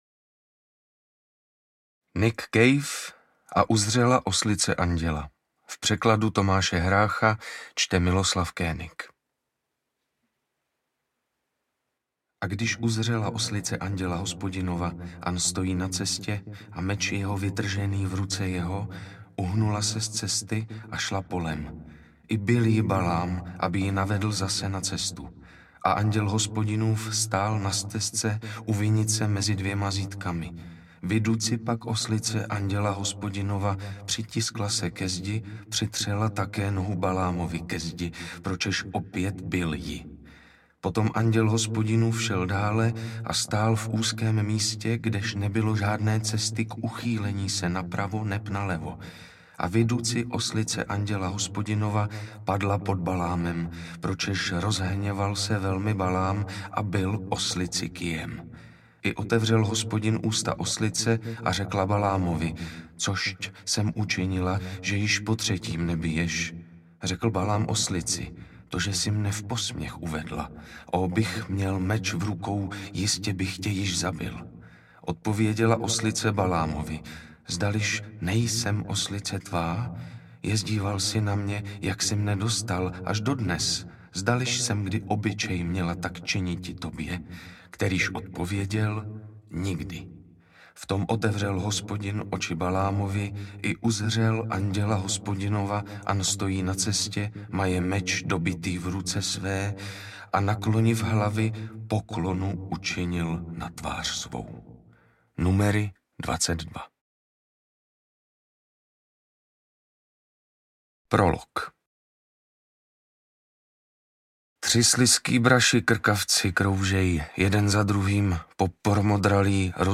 Interpret:  Miloslav König